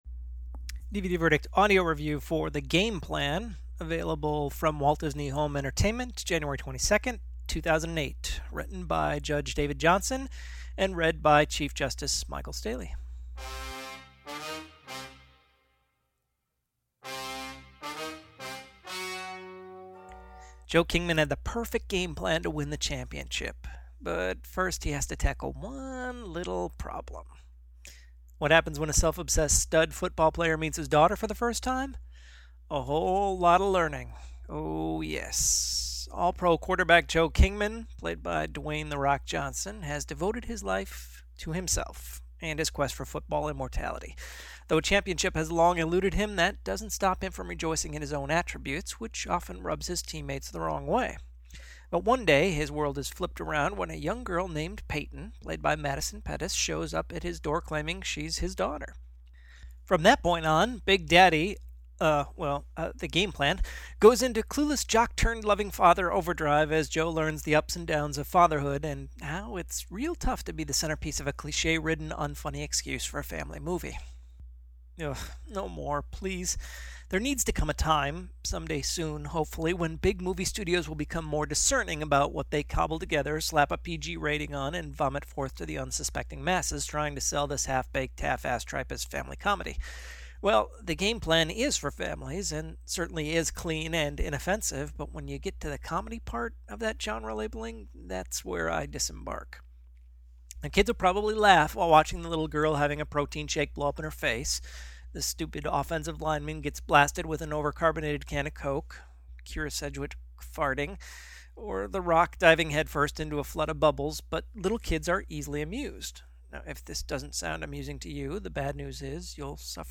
DVD Verdict Audio Review